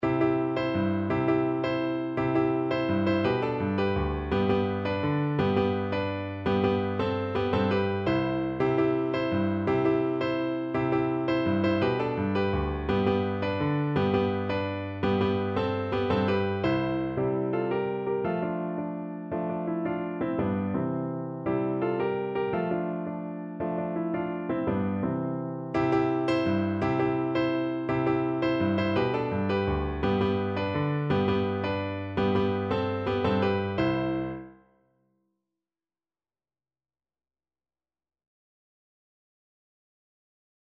Piano version
No parts available for this pieces as it is for solo piano.
3/4 (View more 3/4 Music)
Fast and energetic =c.168
world (View more world Piano Music)